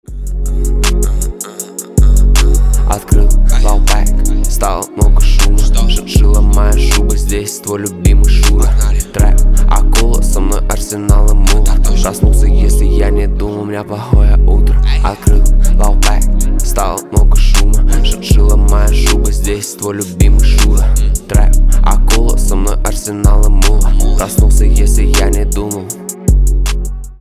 басы
рэп , трэп